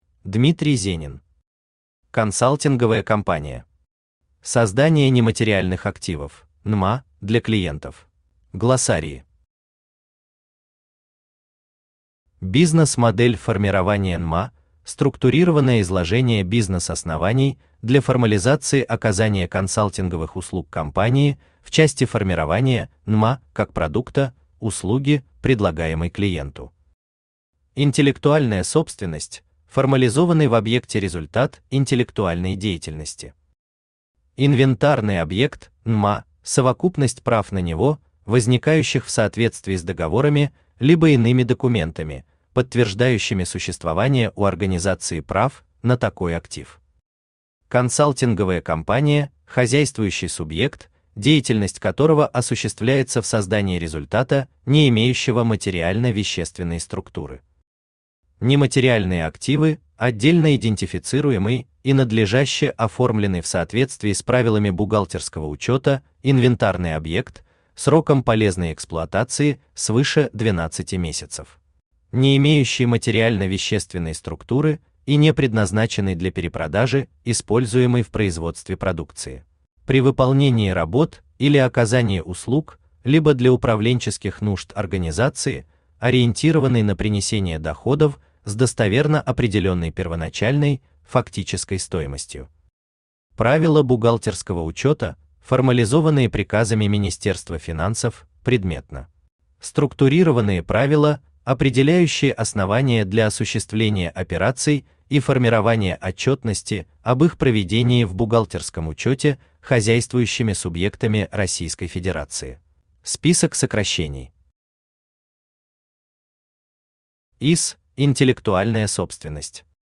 Создание нематериальных активов (НМА) для клиентов Автор Дмитрий Валерьевич Зенин Читает аудиокнигу Авточтец ЛитРес.